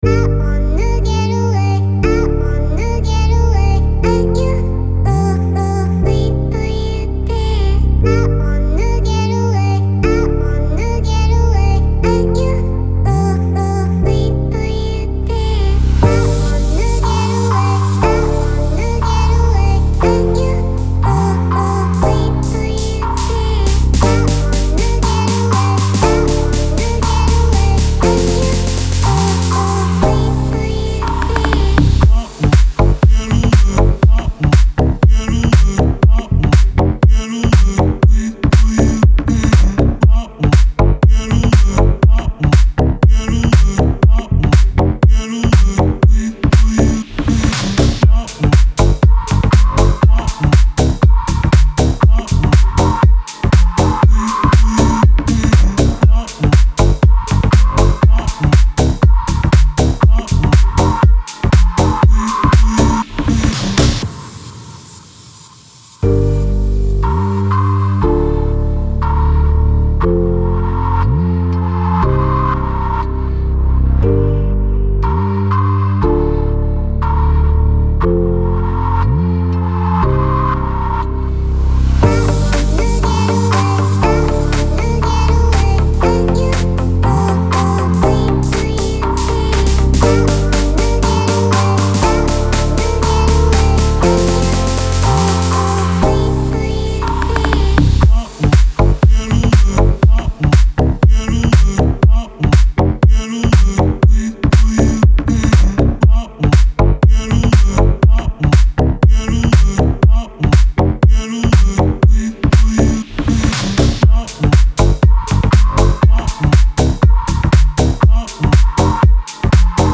это энергичная поп-песня с элементами электронной музыки